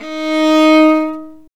Index of /90_sSampleCDs/Roland - String Master Series/STR_Viola Solo/STR_Vla3 % + dyn
STR VIOLA 07.wav